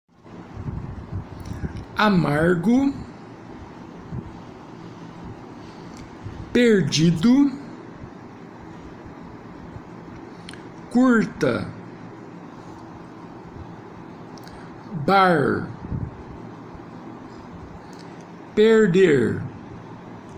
Faça download dos arquivos de áudio e ouça a pronúncia das palavras a seguir para transcrevê-las foneticamente.
GRUPO 6 Retroflexa - Arquivo de áudio -->